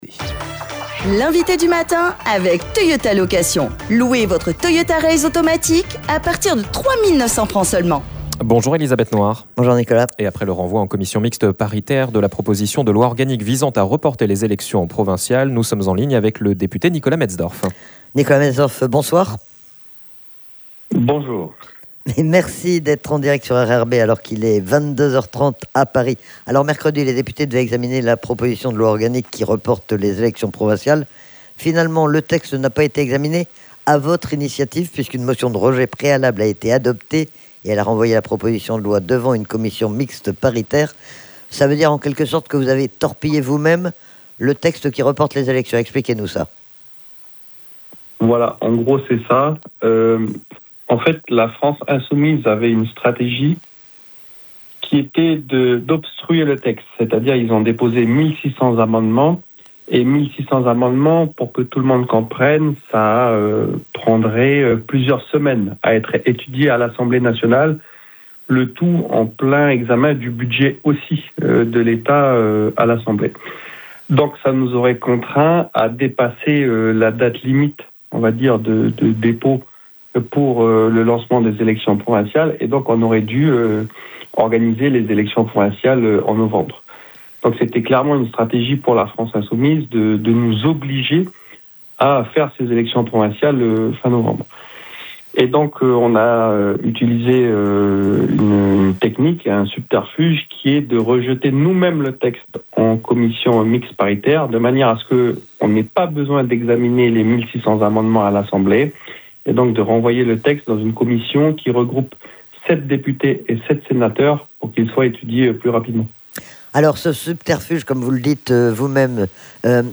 Le député de la première circonscription Nicolas Metzdorf est notre invité du matin depuis Paris. Il revient sur la motion de rejet préalable qui a renvoyé en commission mixte paritaire la proposition de loi organique visant à reporter les élections provinciales.